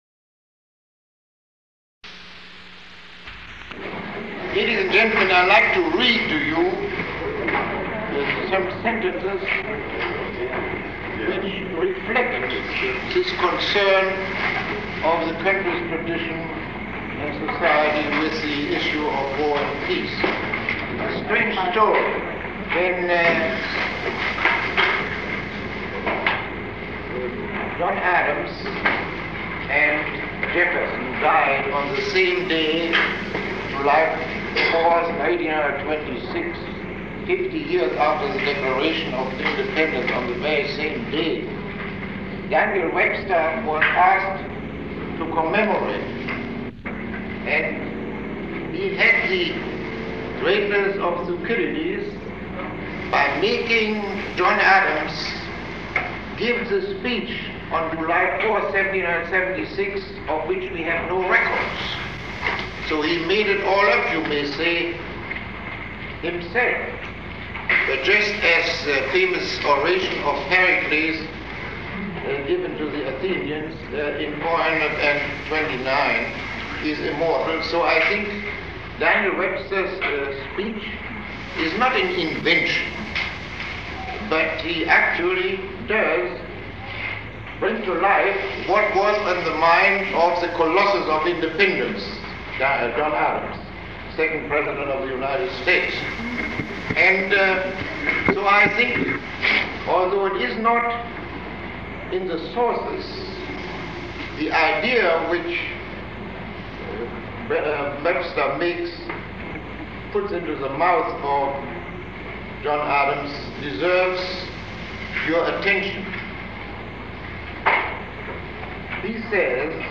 Lecture 08